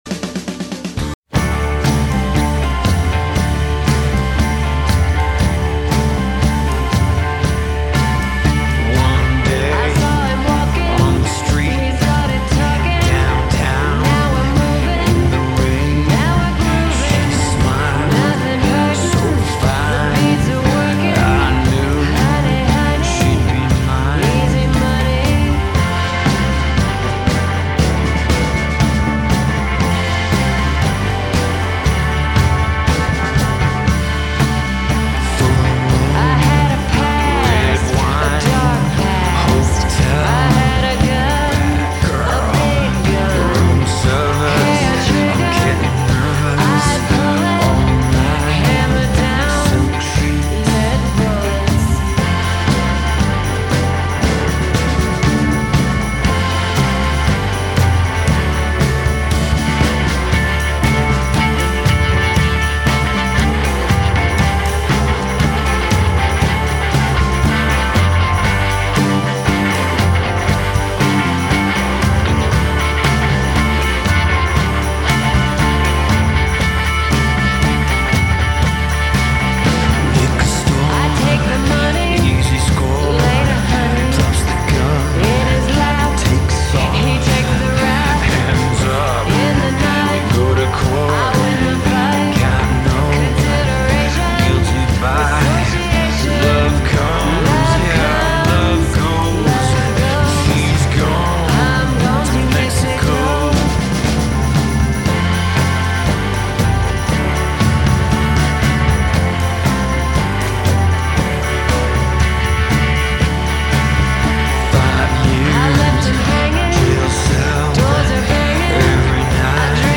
Между первым и вторым треком забыл выровнять громкость.